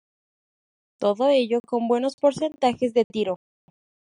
Pronounced as (IPA) /ˈtiɾo/